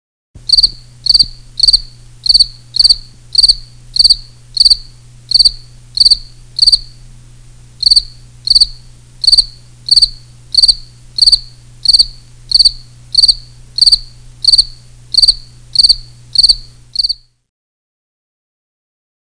il stridule
grillon.mp3